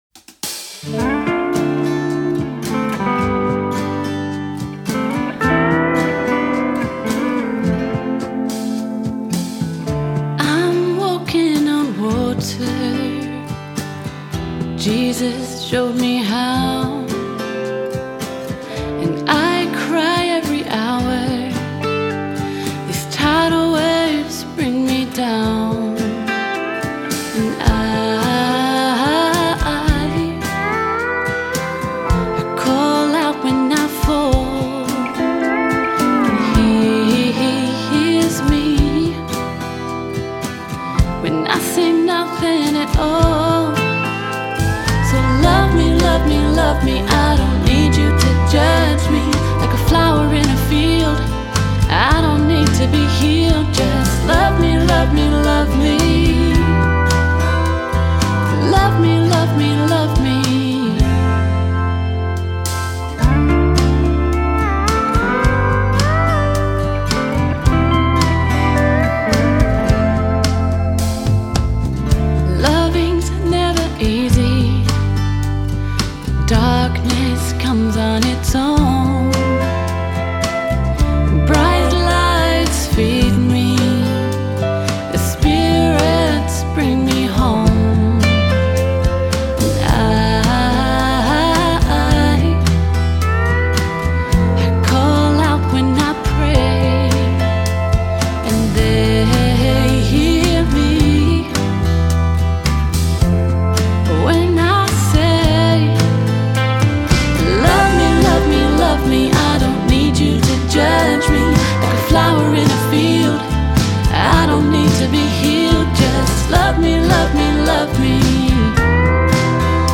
a smooth, sensuous song